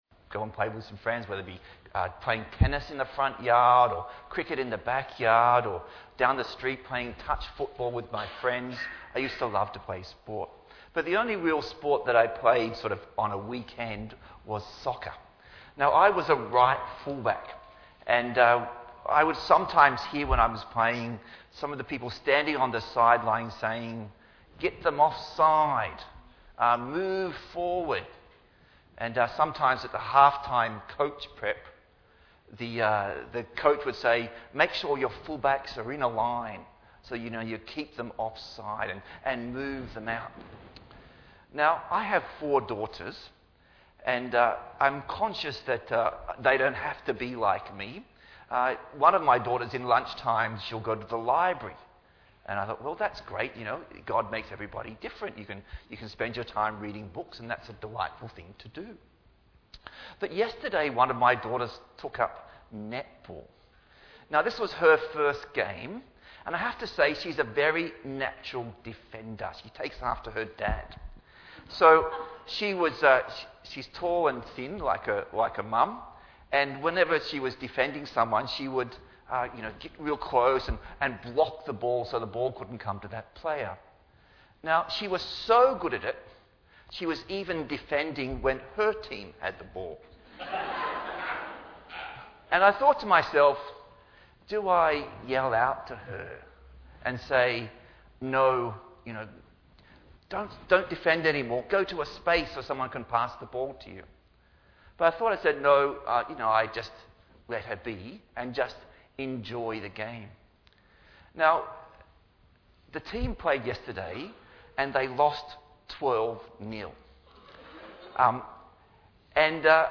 Bible Text: Philippians 1:1-11 | Preacher